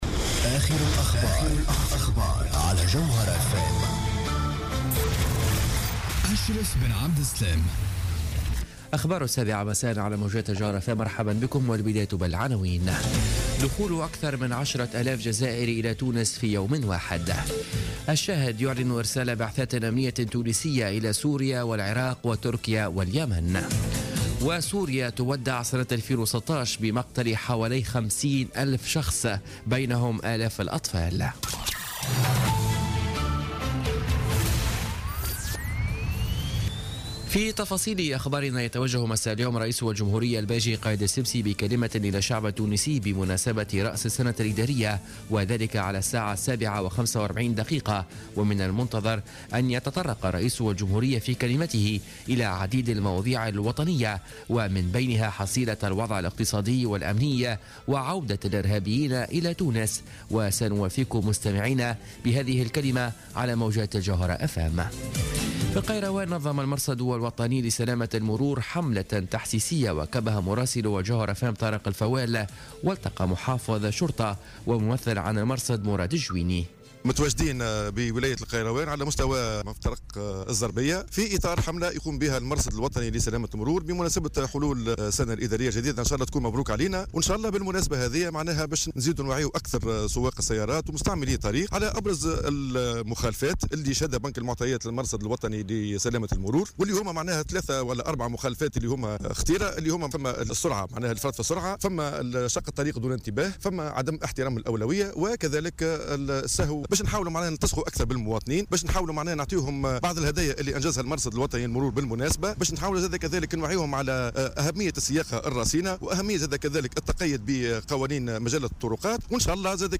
Journal Info 19h00 du samedi 31 Décembre 2016